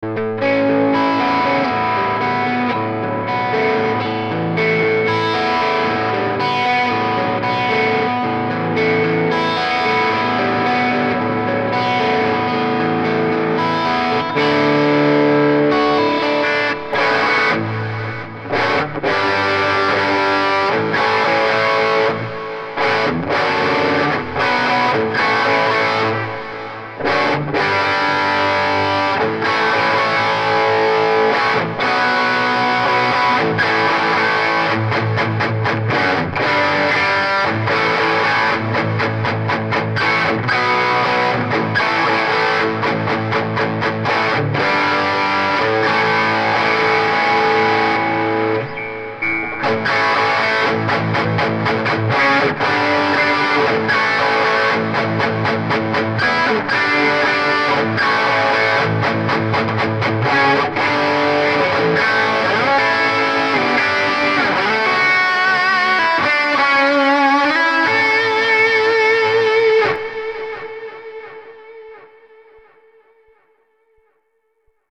This Amp Clone rig pack is made from an Tone King Imperial MKII preamp.
RAW AUDIO CLIPS ONLY, NO POST-PROCESSING EFFECTS